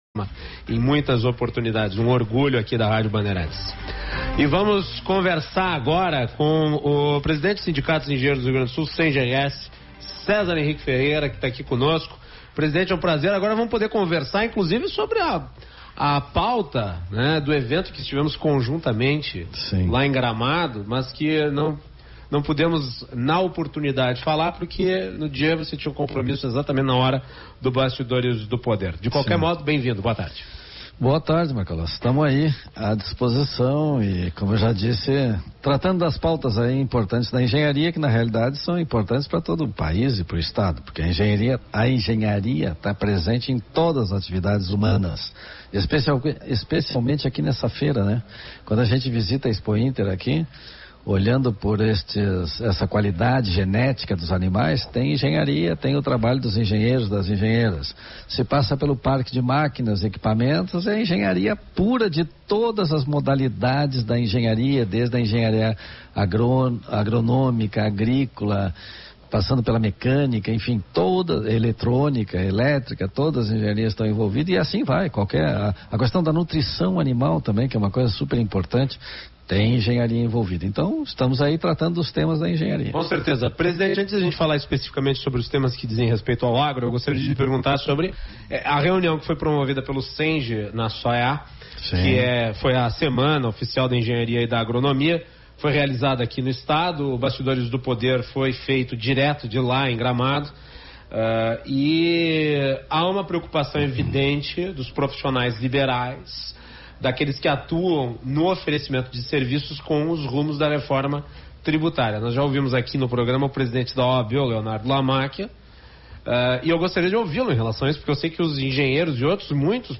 Entrevista-rD-bANDEIRANTES-bASTIDORES-DO-pODER.mp3